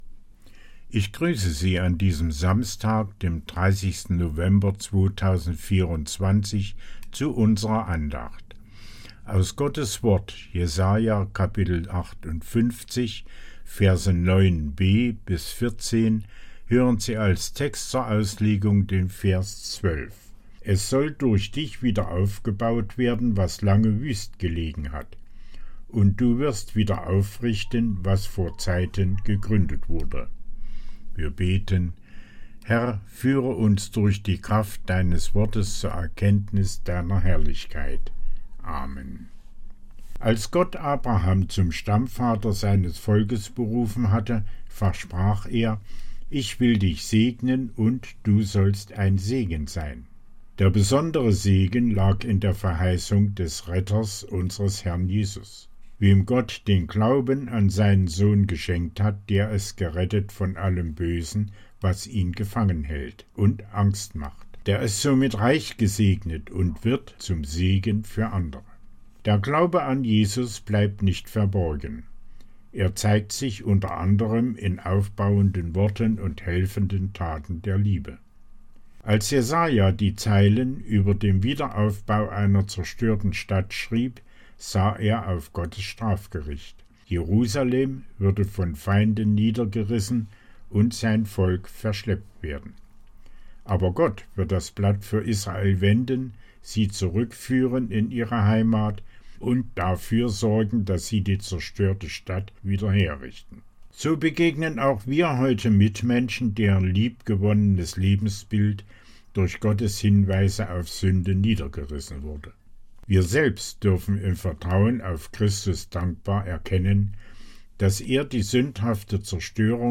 Tägliche Andachten aus dem Andachtsheft der Ev.-Luth. Freikirche